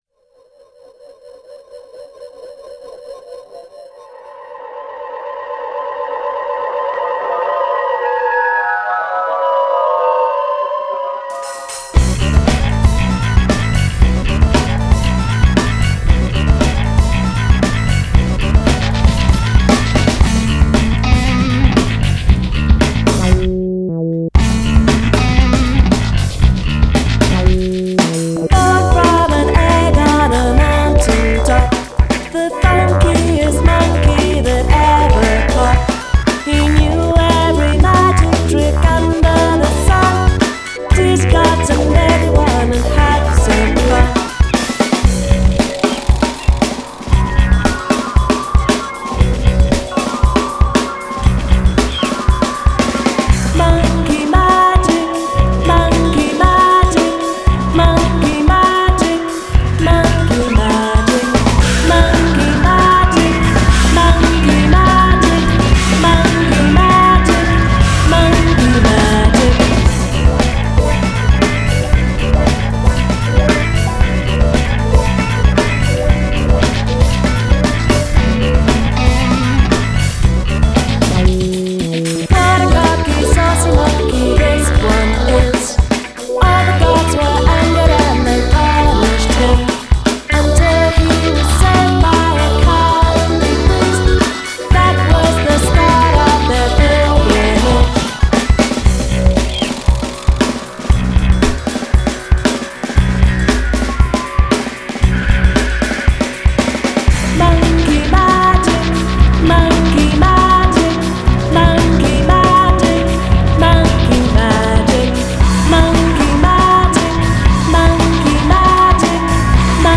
British group